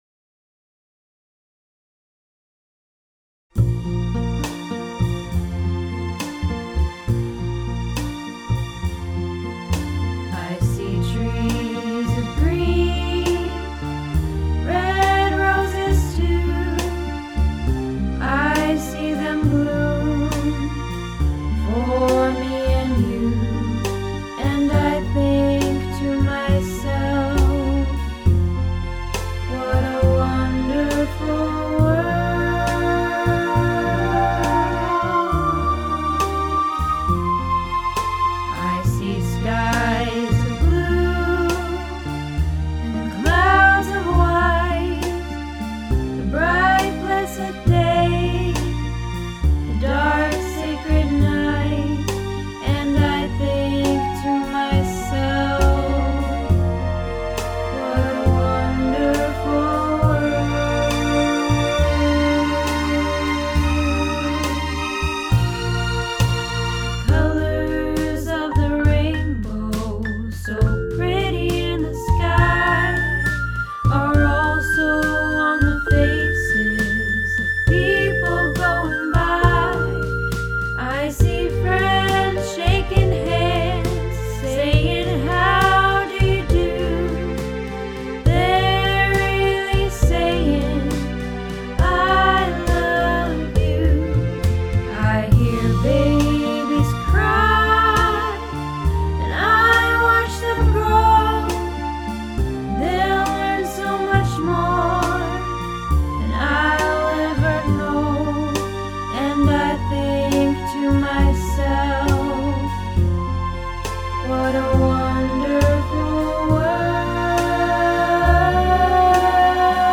What a Wonderful World - Tenor